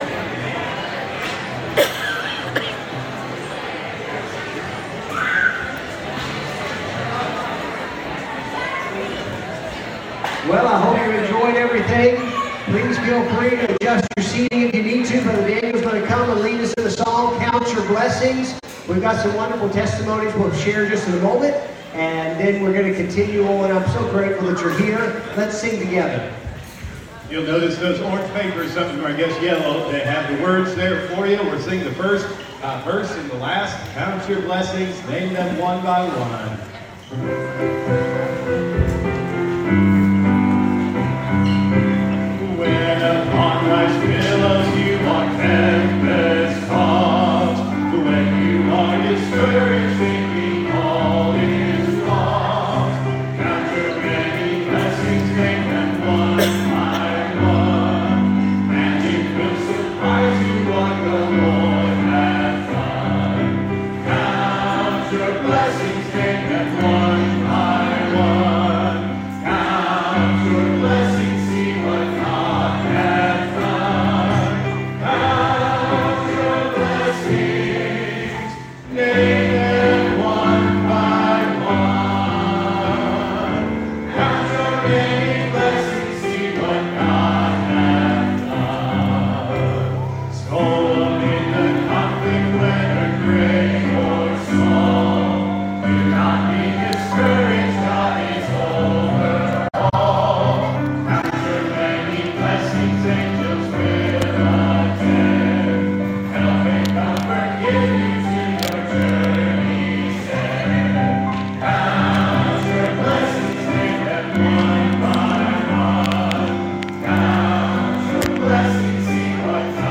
Wednesday evening service at Buffalo Ridge Baptist Church in Gray, Tn.